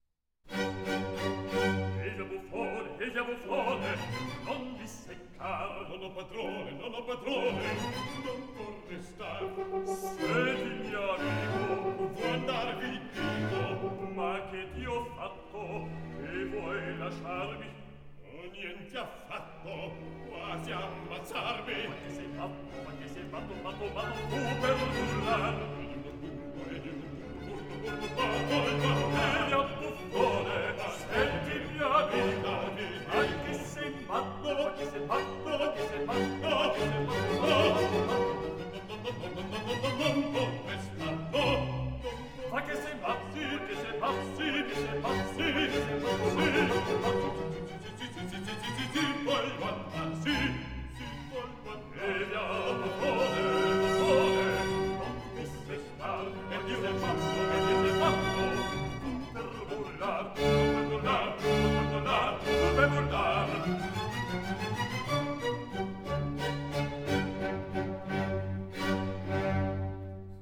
Duetto.